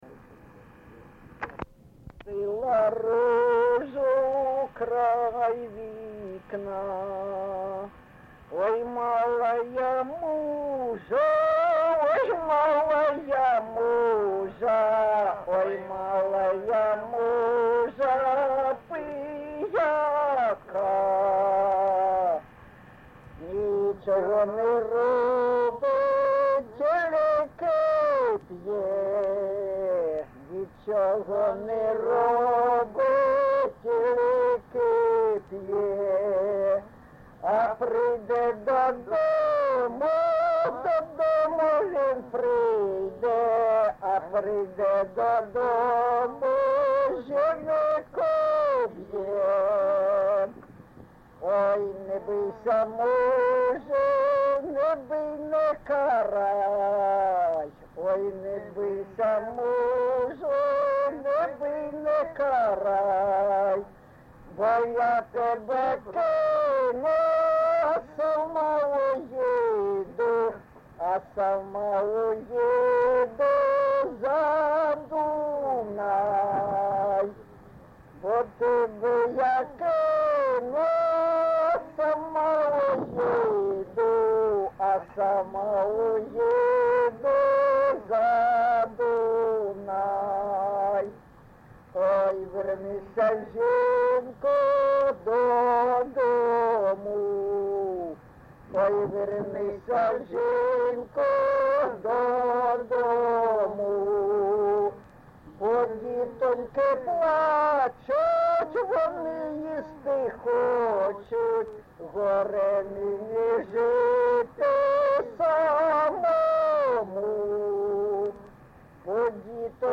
ЖанрПісні з особистого та родинного життя
Місце записум. Дебальцеве, Горлівський район, Донецька обл., Україна, Слобожанщина